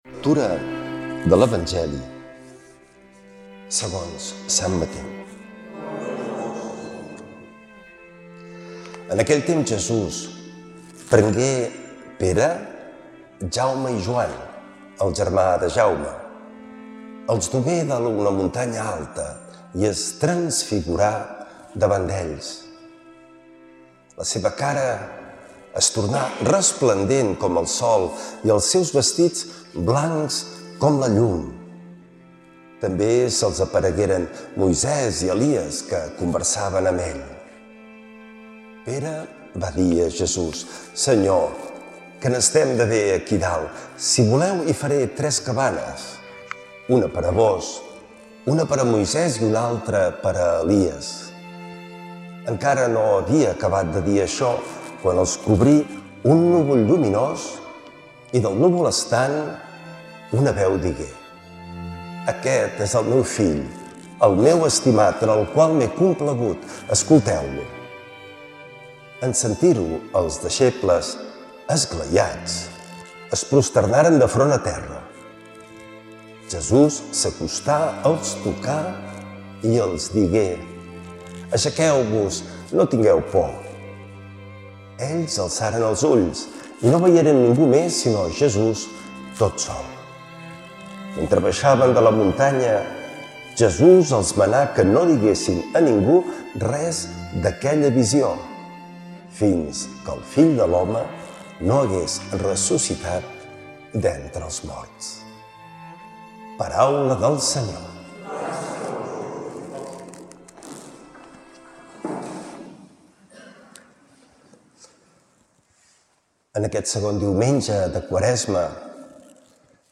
L’Evangeli i el comentari de diumenge 1 març del 2026.
Lectura de l’evangeli segons usant Mateu